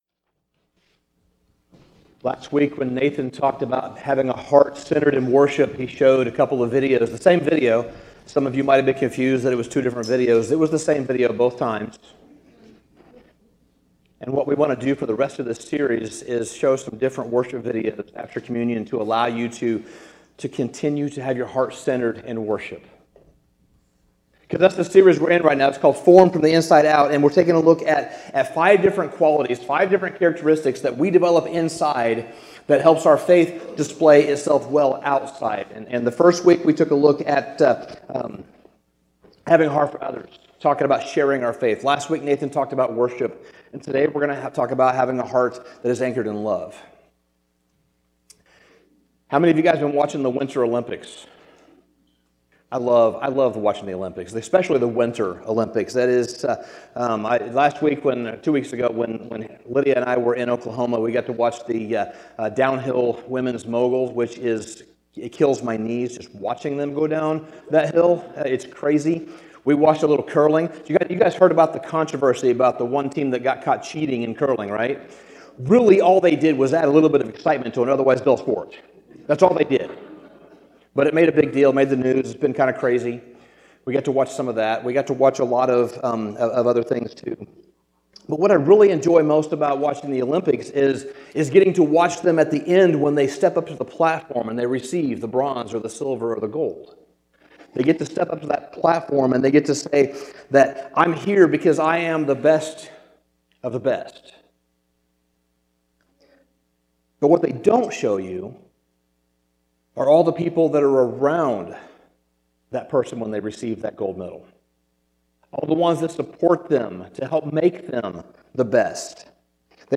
Sermon Summary Our hearts were never meant to drift alone.